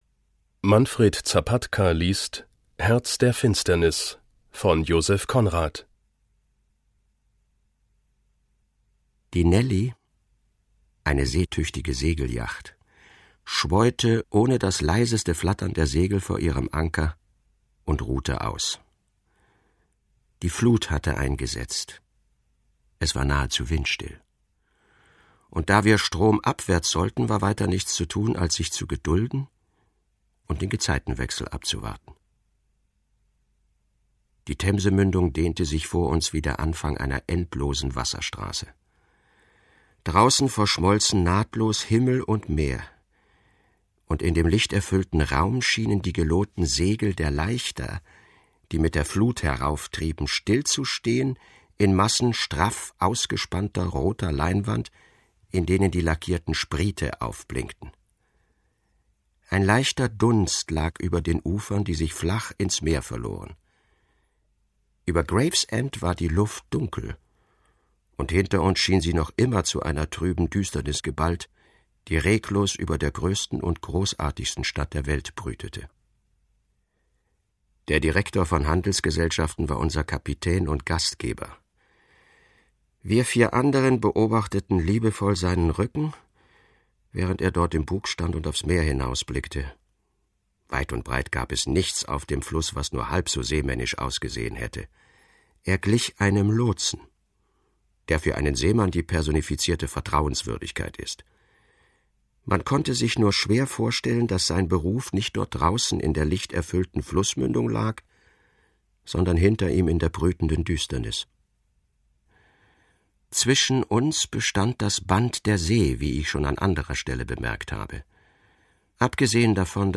Manfred Zapatka (Sprecher)